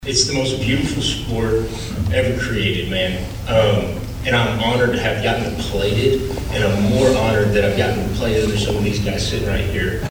The Brenham Cub Football Hall of Honor welcomed four new members into their ranks before a packed house at the Blinn College Student Center this (Friday) afternoon.